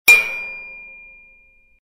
Звуки удара по металлу